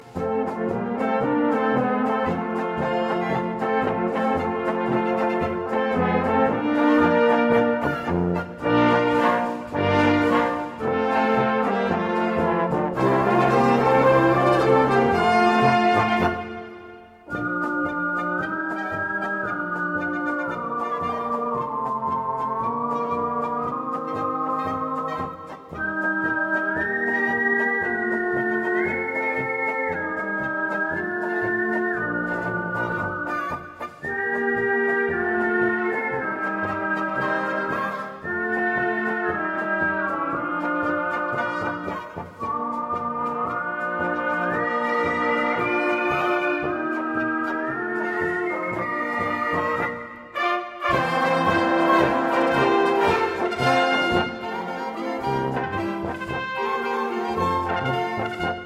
Gattung: Polka mit Pfeif-Solo
2:46 Minuten Besetzung: Blasorchester Zu hören auf